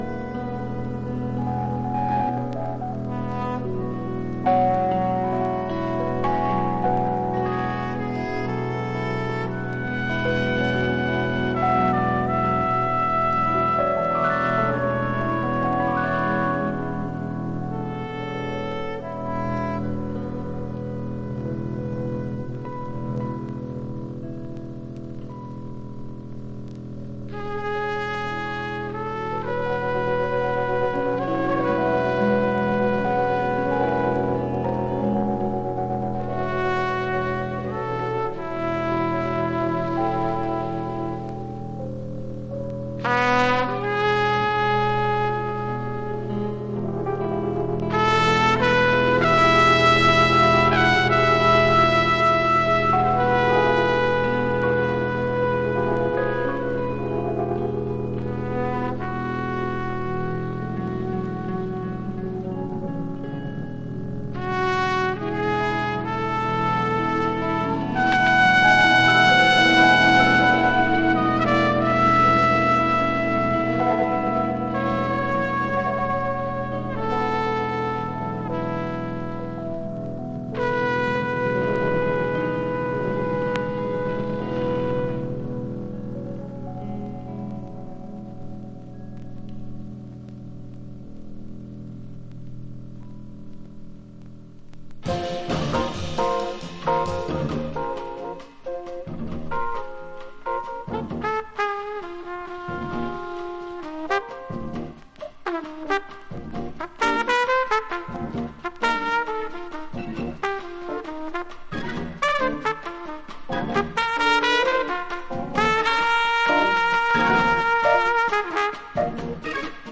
（プレス・小傷によりチリ、プチ音ある曲あり）※曲…